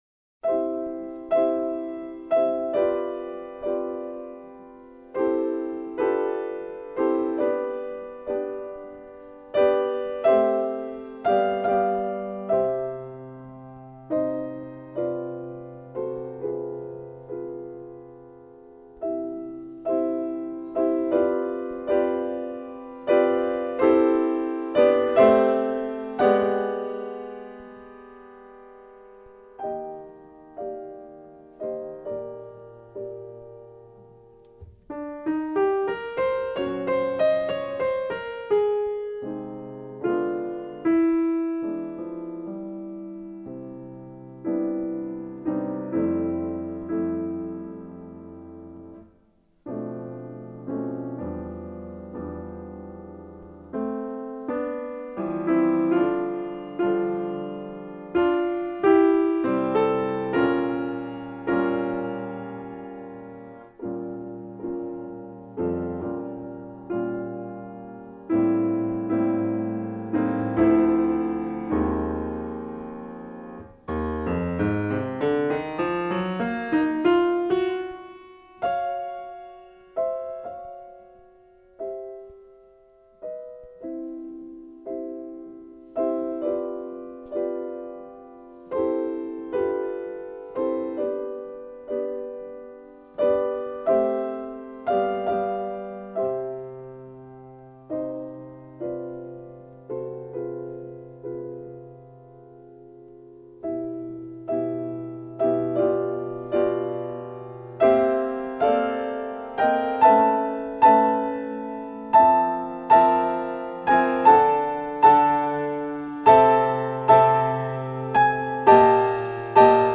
Prelude and Fugue num. 1, in C with the fugue on the white keys only.